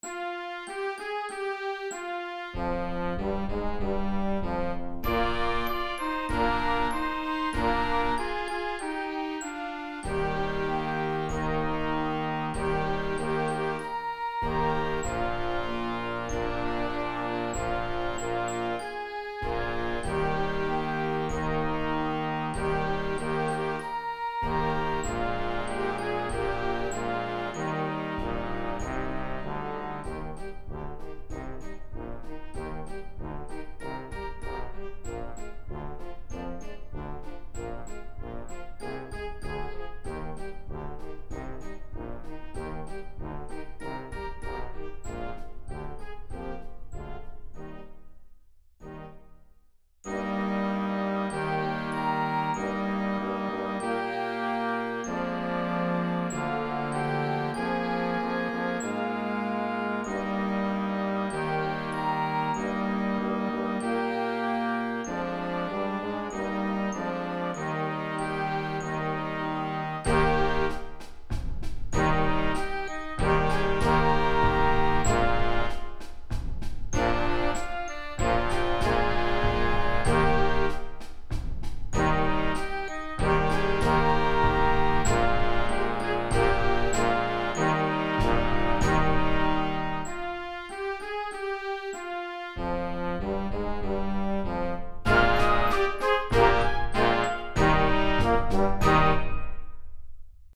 Beginning Band Arrangement
CHILDREN'S MUSIC